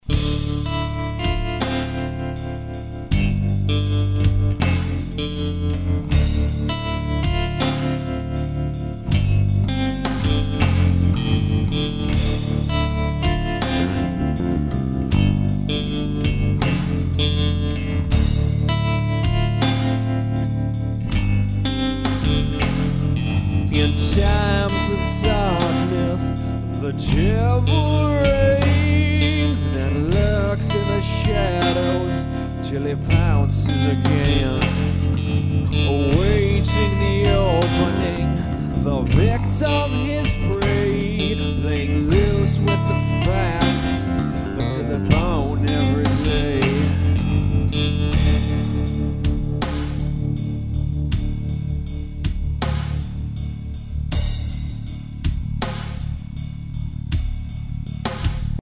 DarkTime - A vocal idea with some sparse instrumentation to support the melody.